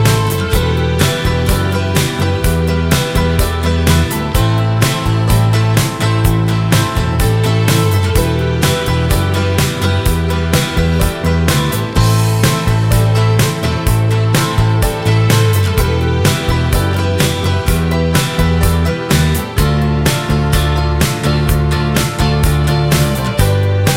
Indie / Alternative